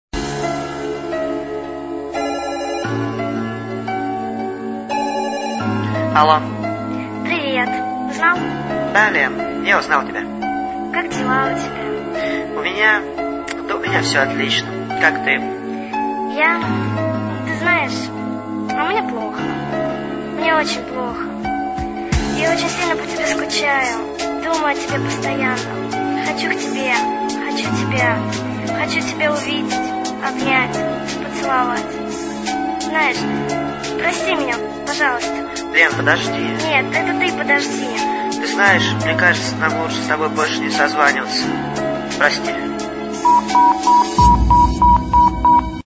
Диалог парня и девушки..